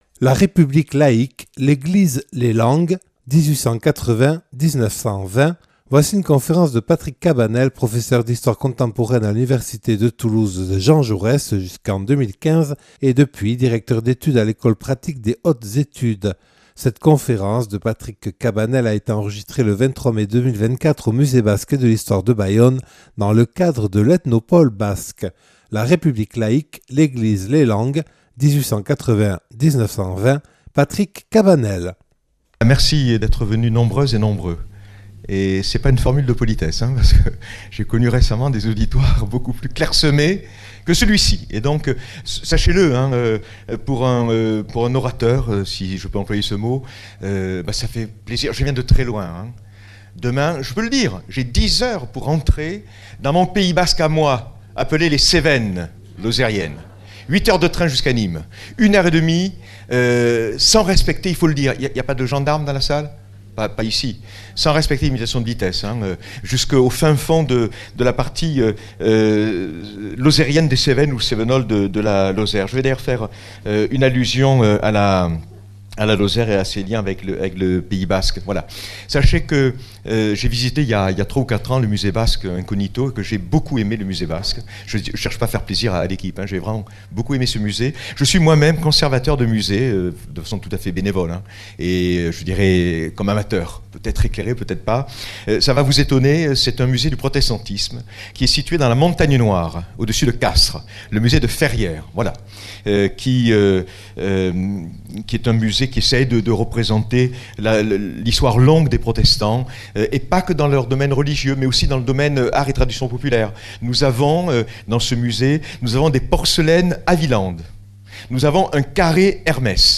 (Enregistré le 23/05/2024 au Musée Basque et de l’histoire de Bayonne dans le cadre de l’Ethnopôle Basque).